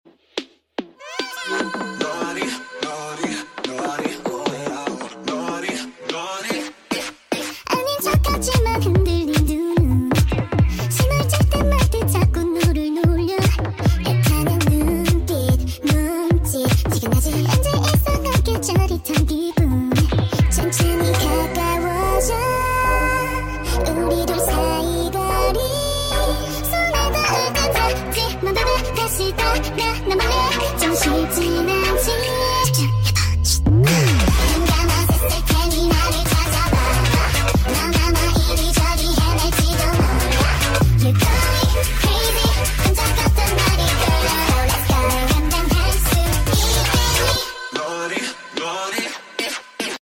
( Short Version )